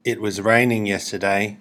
itwasrainingFAST.mp3